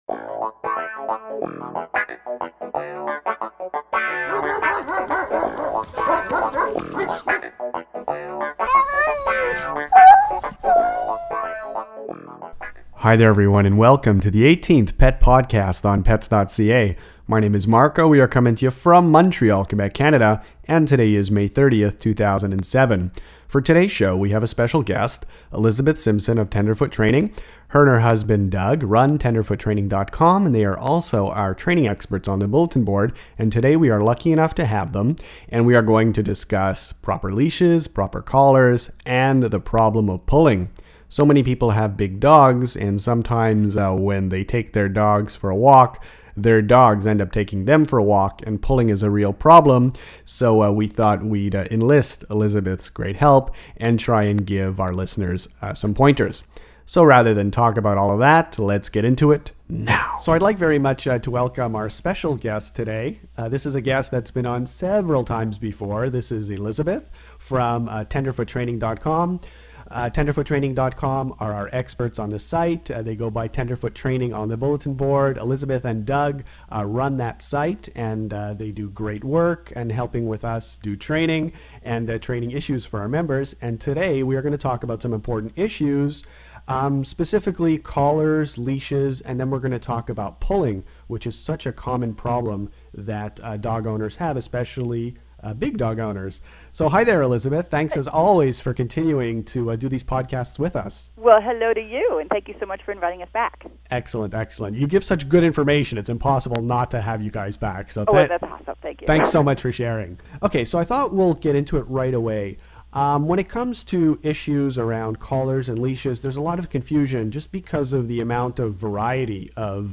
Dogs that pull – dog collars – dog leashes – Pet podcast #18 – Interview